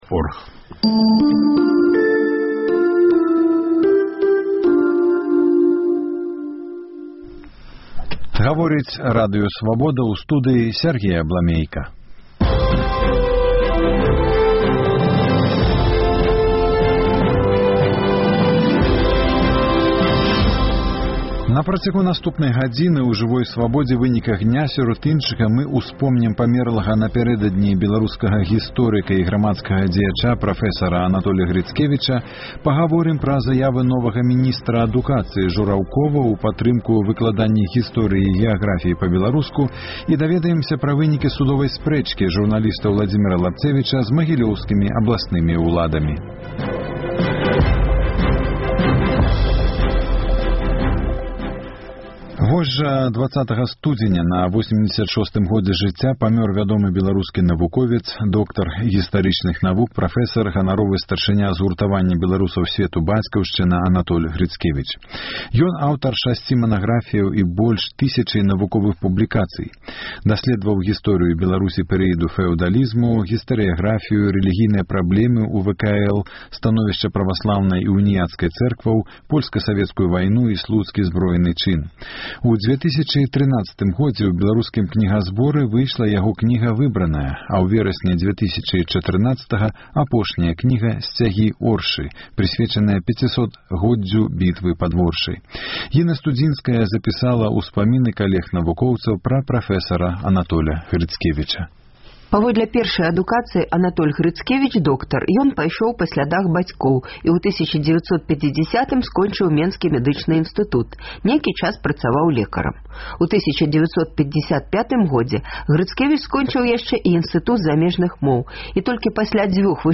Адказваюць жыхары Гомеля. 20 студзеня на 86-годзе жыцьця памёр вядомы беларускі навуковец, доктар гістарычных навук, прафэсар Анатоль Грыцкевіч. Адпяваньне пройдзе 22 студзеня ў Чырвоным касьцёле ў Менску паводле грэка-каталіцкага абраду.